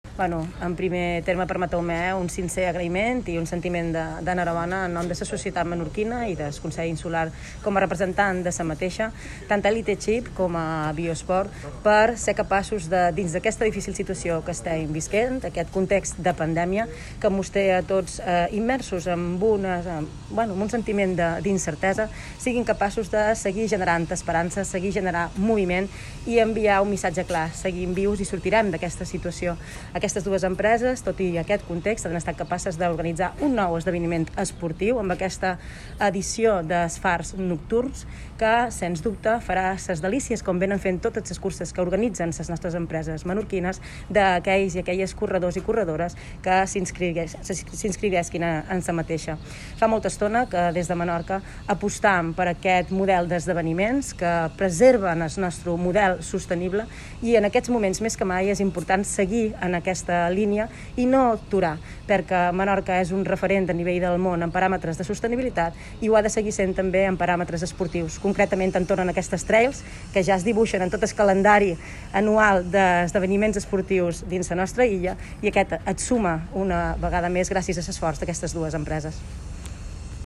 Susana Mora, presidenta Consell Insular de Menorca